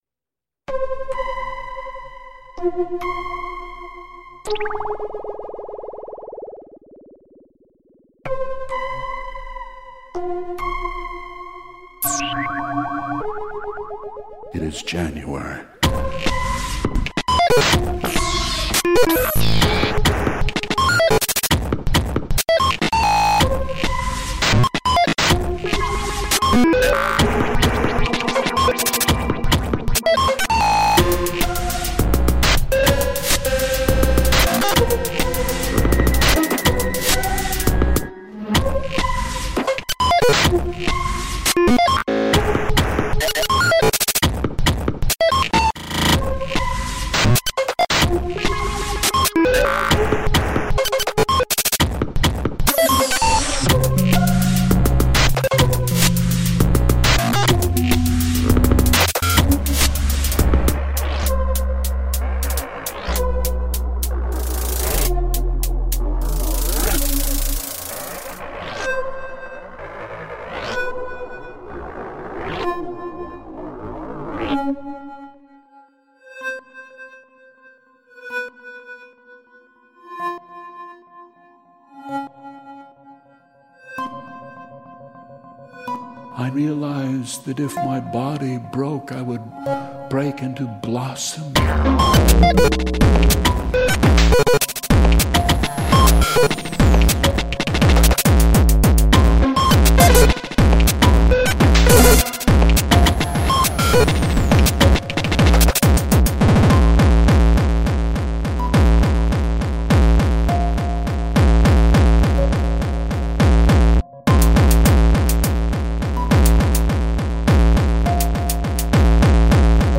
An office worker in Memphis, Tennessee plays music from his record collection, with a focus on post-punk, electronic music, dub, and disco.